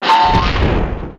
box break.wav